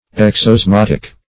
Exosmotic \Ex`os*mot`ic\, a. Pertaining to exosmose.